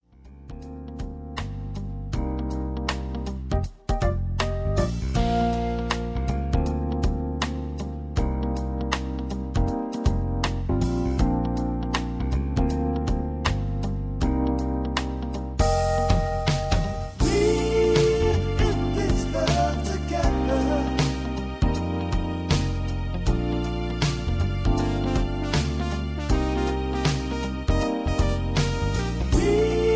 (Version-1, Key-Bb)Karaoke MP3 Backing Tracks